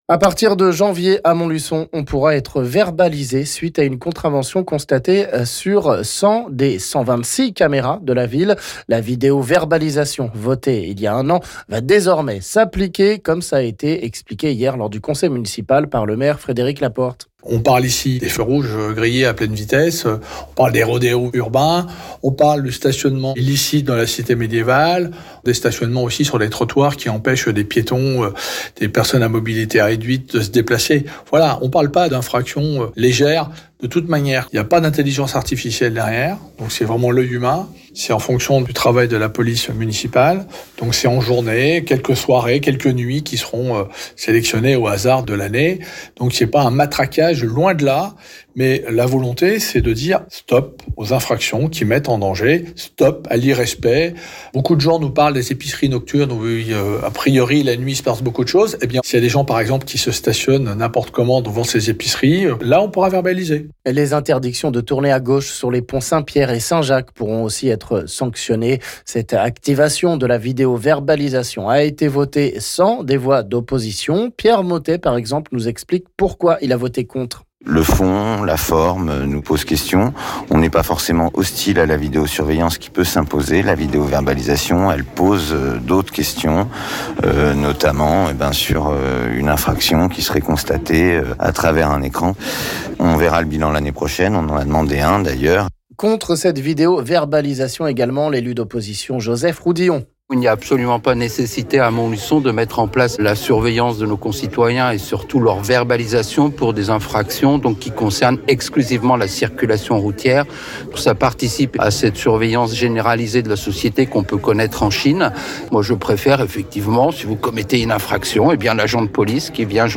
On écoute ici les explications du maire Frédéric Laporte, et réactions des élus d'opposition Pierre Mothet et Joseph Roudillon...